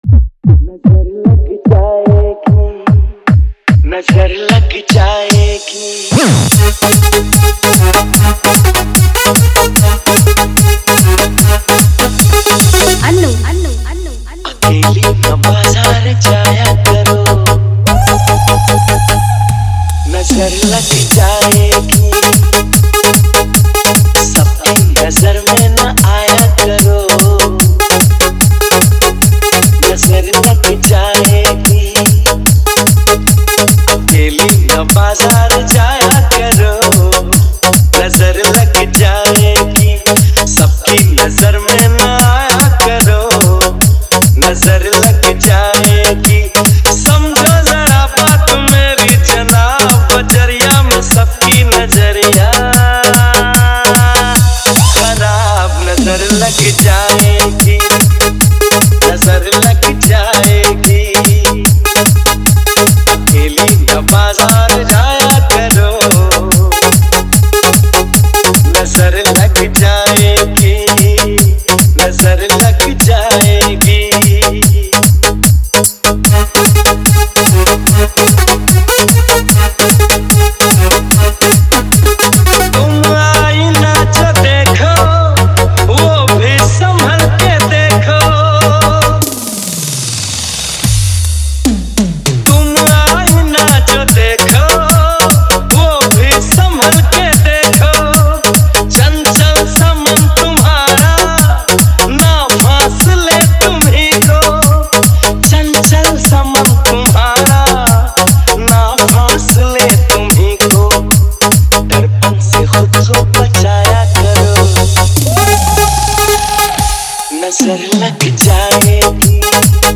Bhojpuri Old Dj Remix